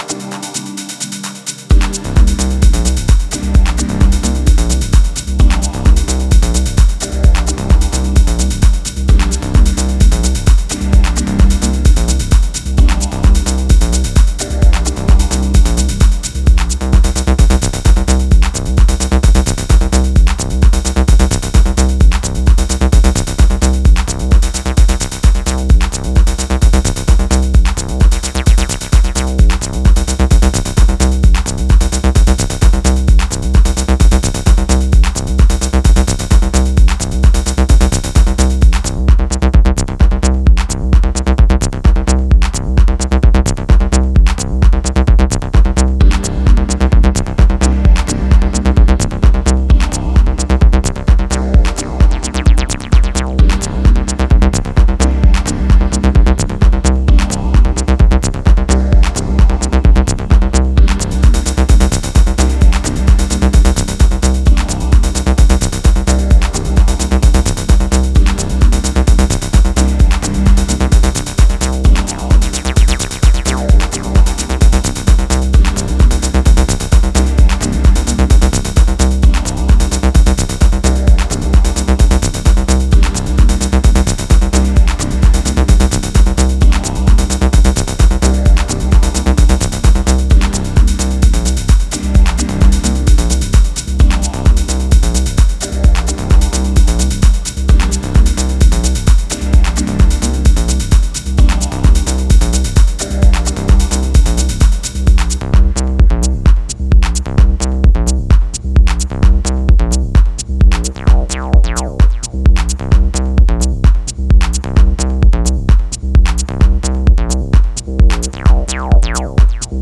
one of the most creative Techno producers in recent years